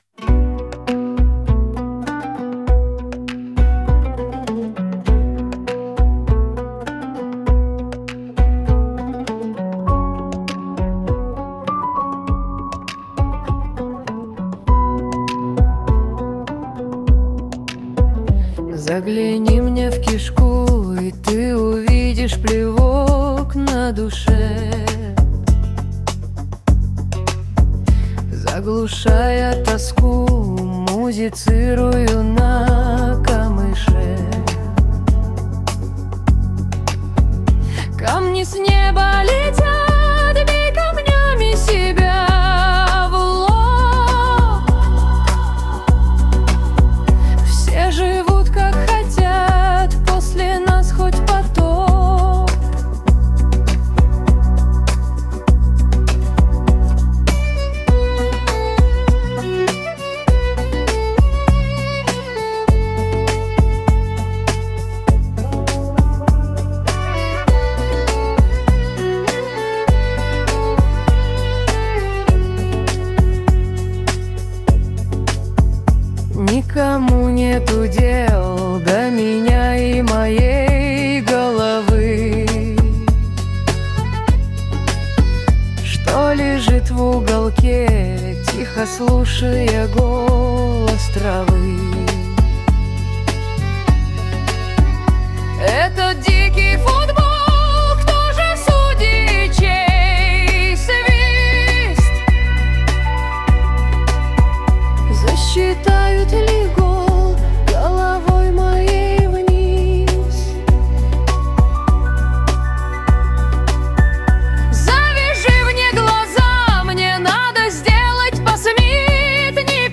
• Аранжировка: Collab
• Жанр: AI Generated